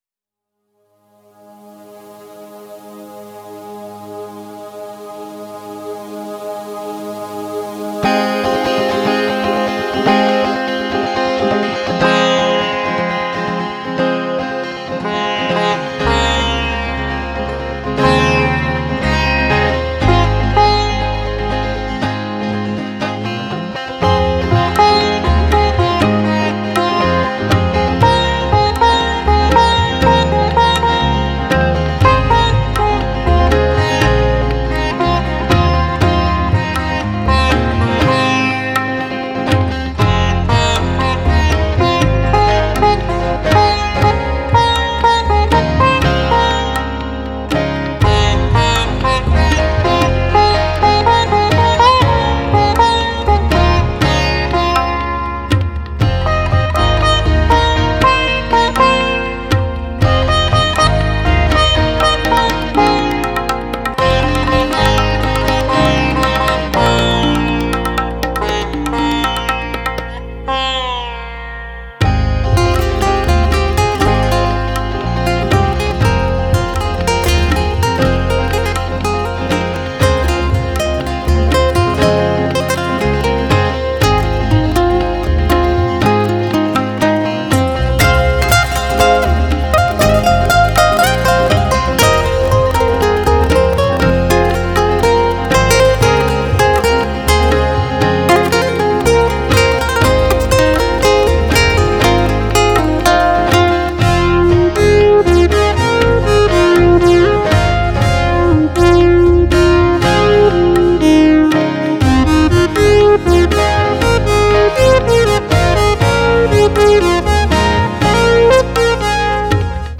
Alle Stücke auf dieser Seite liegen in CD-Qualität vor.